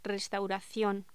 Locución: Restauración
voz
Sonidos: Voz humana